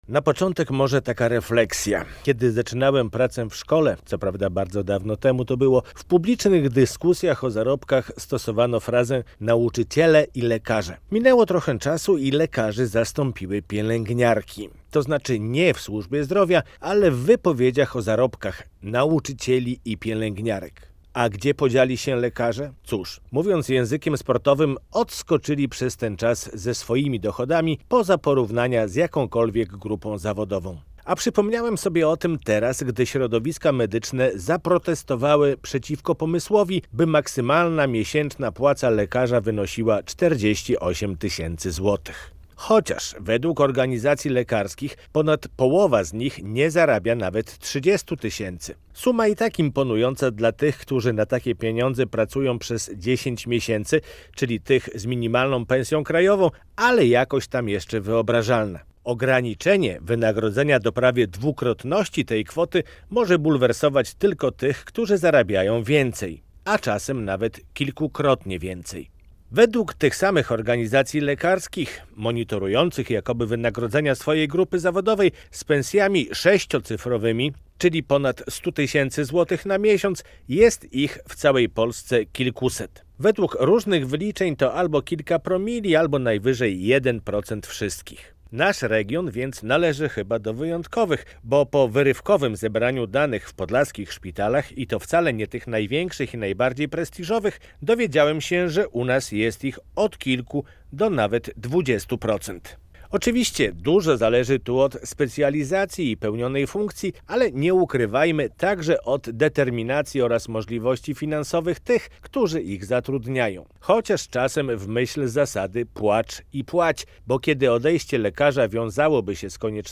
Radio Białystok | Felieton